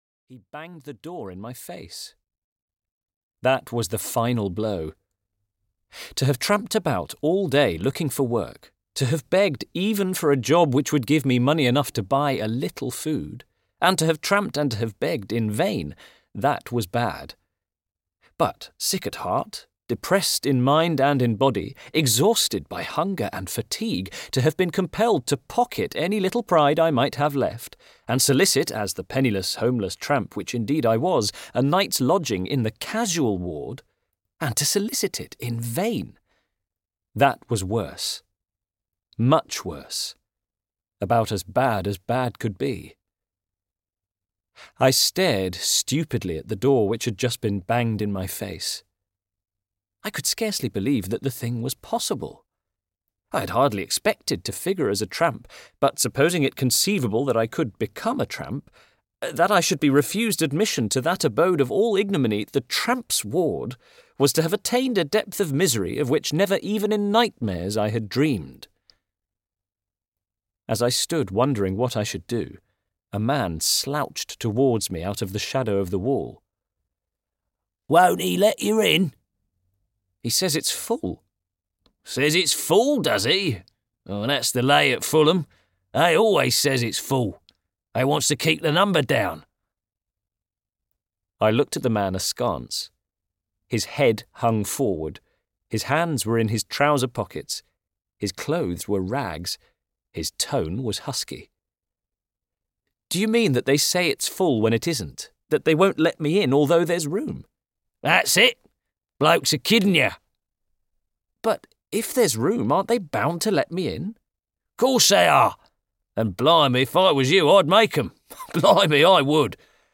The Beetle (EN) audiokniha
Ukázka z knihy